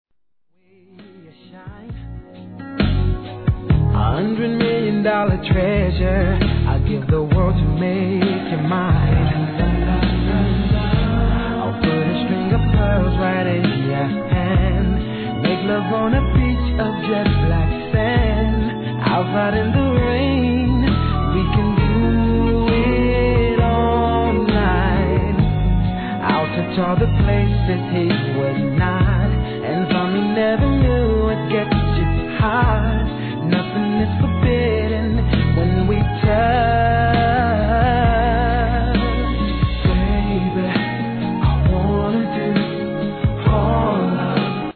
1. HIP HOP/R&B
スロウでムーディー、甘〜いセレクトで今回もバカ売れ確実！！